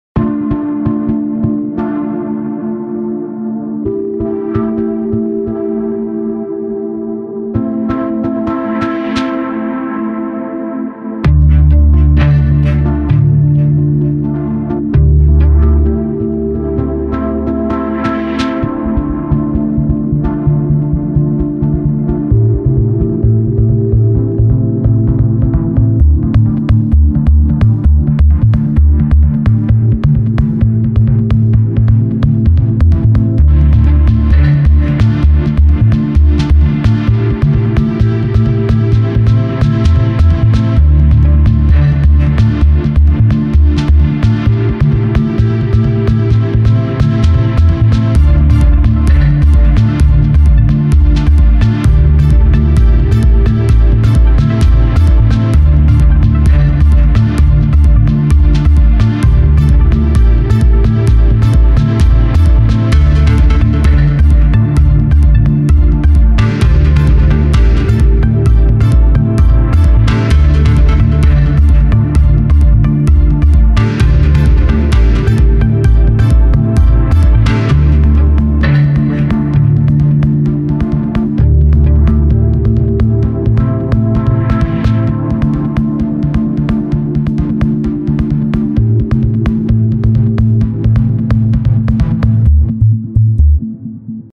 Clubby